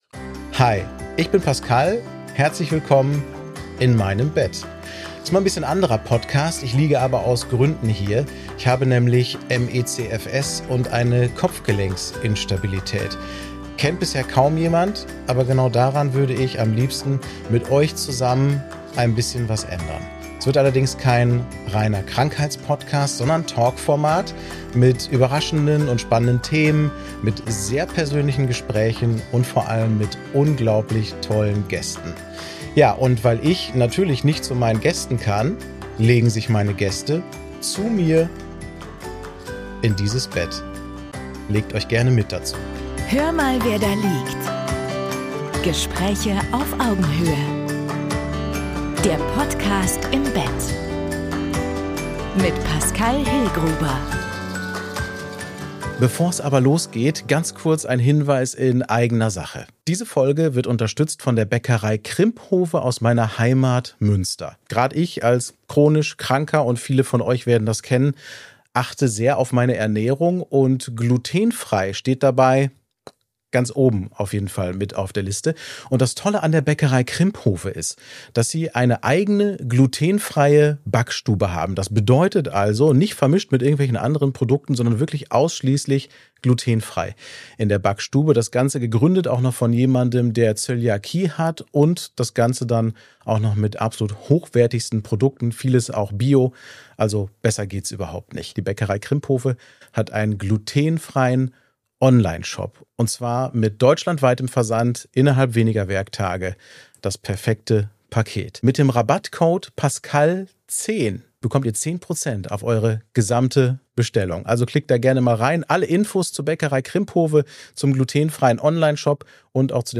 Ein sachliches Gespräch über Transparenz, Geheimhaltung und Medienkompetenz.